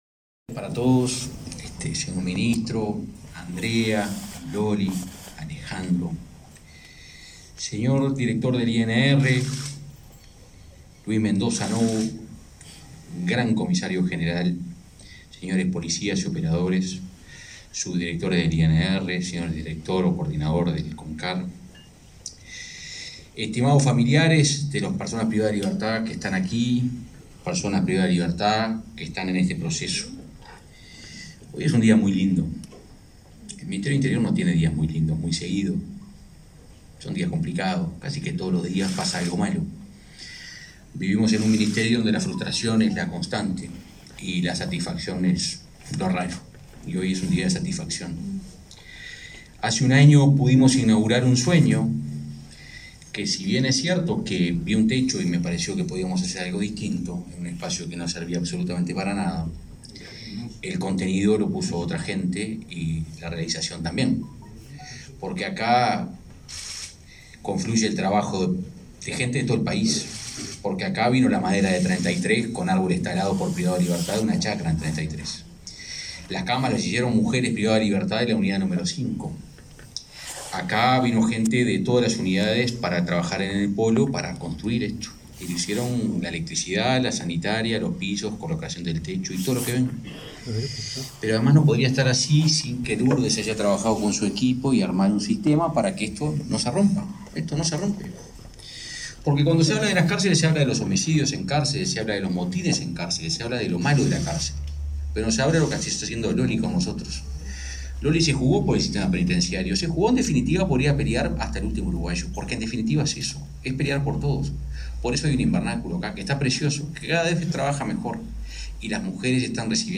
Conferencia de prensa por el aniversario del preegreso de la Unidad 4 del INR